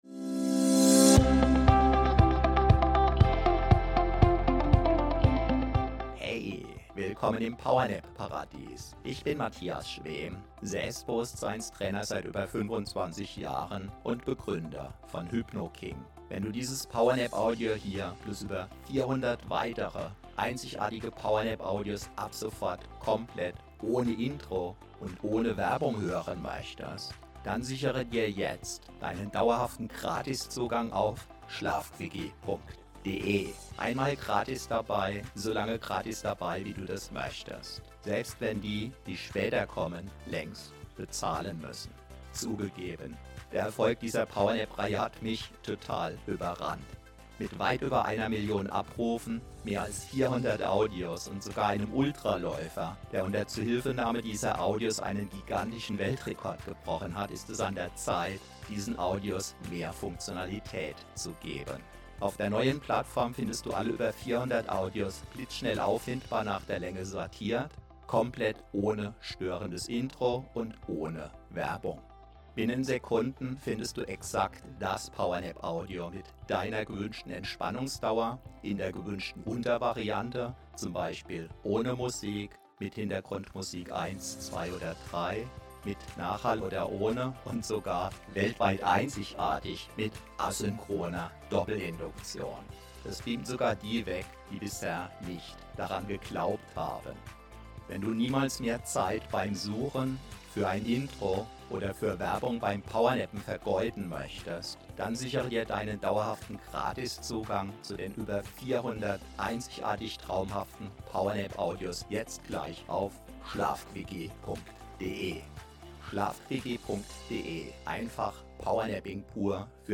Denn: Diese 8 min Power Nap schenkt dir Energie für VIELE STUNDEN.  ACHTUNG:  Die eingebaute asynchrone Doppelinduktion ist so stark, dass es zu SCHWINDELgefühlen kommen kann!